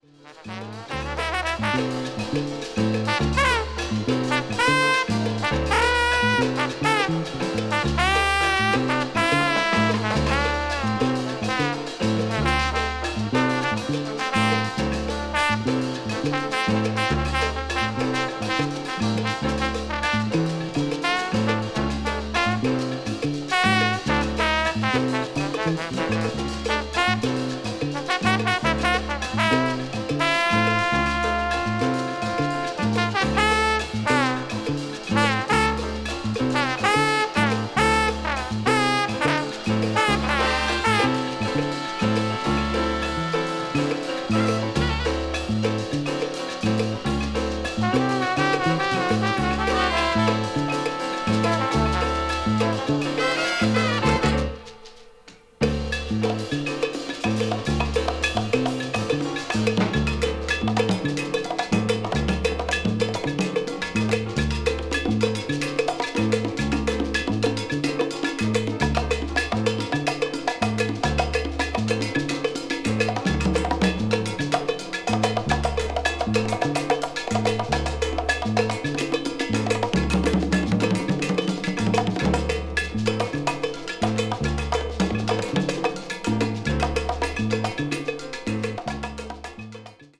media : EX-/VG+(わずかにチリノイズが入る箇所あり,再生音に影響ない薄いスリキズあり)
afro cuban jazz   ethnic jazz   latin jazz   rare groove